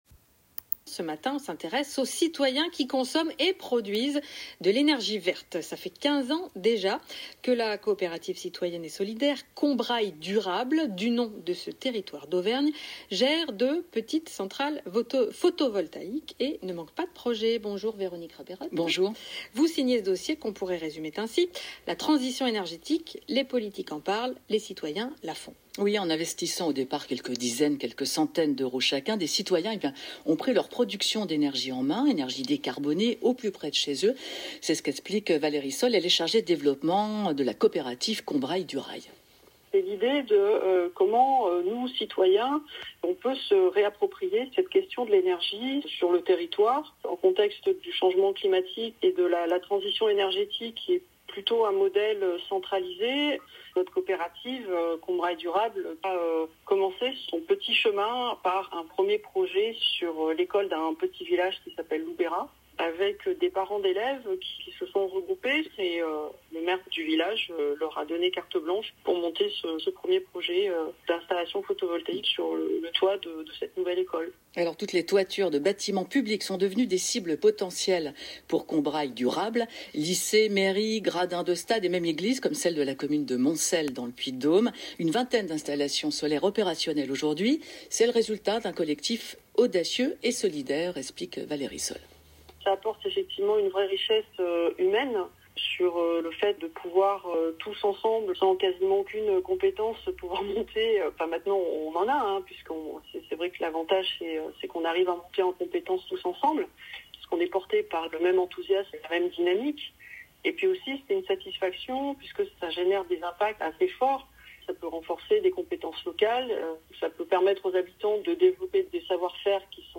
Interview France Culture